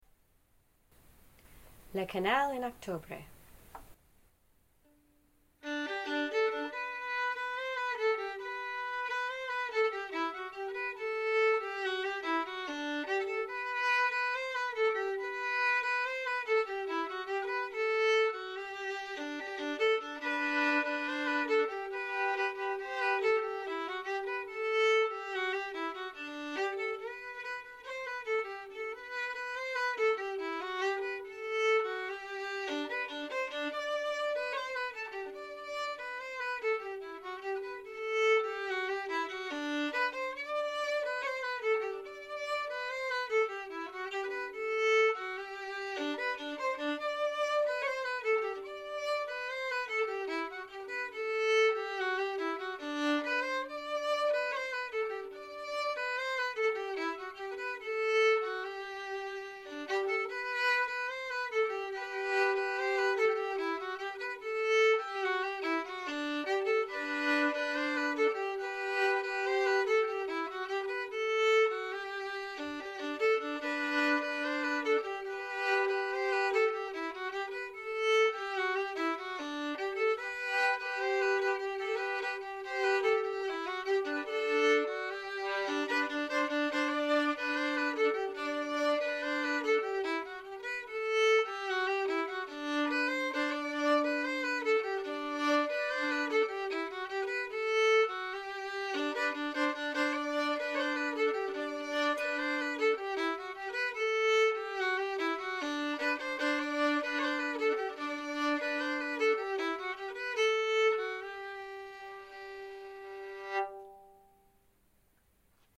Recordings of all the tunes we learn are archived here for future reference.
Composer Frederic Paris Type Reel Key G Recordings